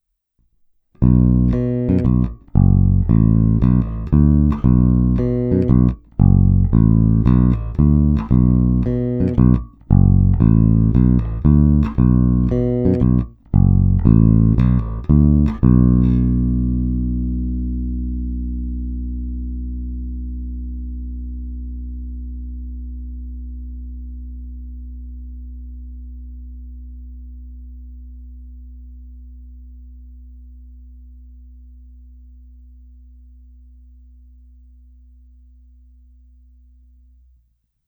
Není-li uvedeno jinak, jsou ukázky nahrány rovnou do zvukové karty a jen normalizovány. Hráno vždy nad aktivním snímačem, v případě obou pak mezi nimi.
Oba snímače